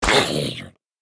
Minecraft Version Minecraft Version latest Latest Release | Latest Snapshot latest / assets / minecraft / sounds / mob / zombie_villager / hurt2.ogg Compare With Compare With Latest Release | Latest Snapshot
hurt2.ogg